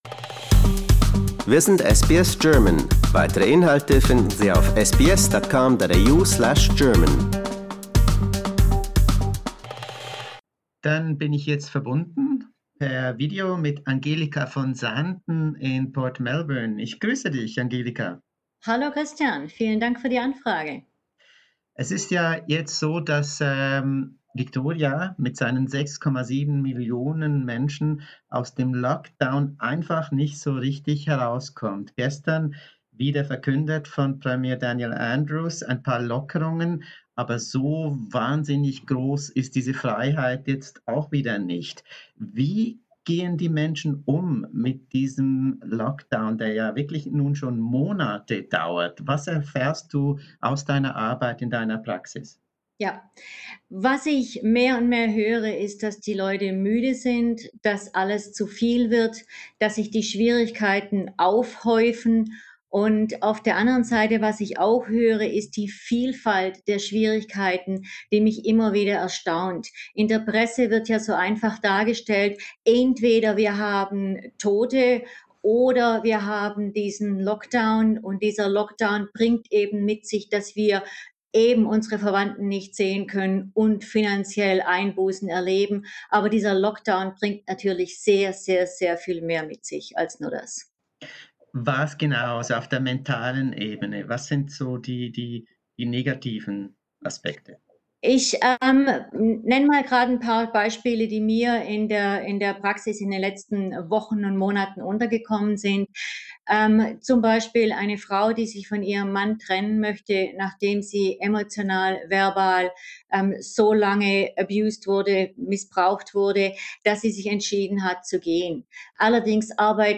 We discuss the issue with a psychological therapist in Melbourne.